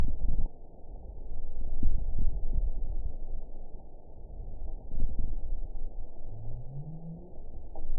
event 921316 date 05/09/24 time 14:23:17 GMT (1 year, 1 month ago) score 7.64 location TSS-AB05 detected by nrw target species NRW annotations +NRW Spectrogram: Frequency (kHz) vs. Time (s) audio not available .wav